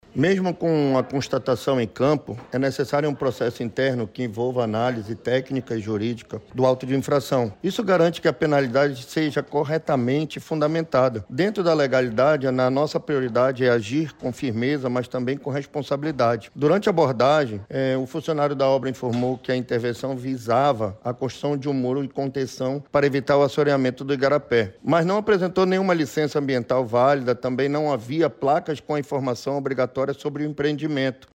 A situação é detalhada pelo diretor-presidente do Ipaam, Gustavo Picanço.